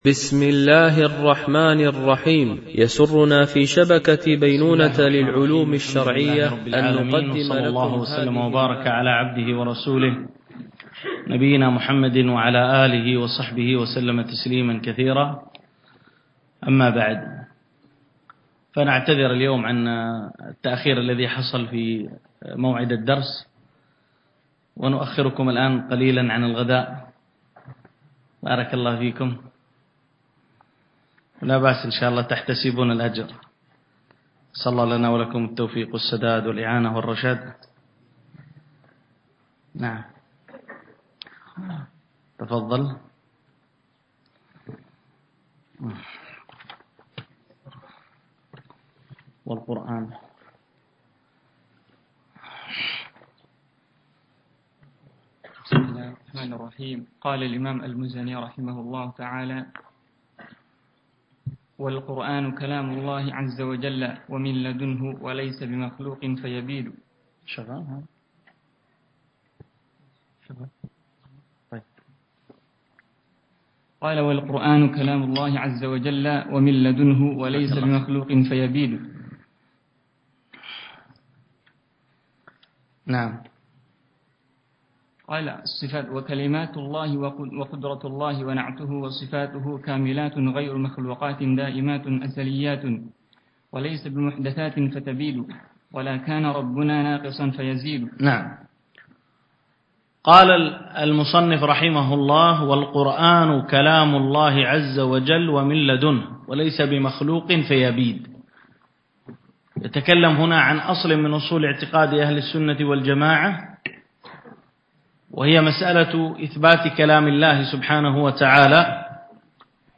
شرح كتاب السنة للمزني ـ الدرس 3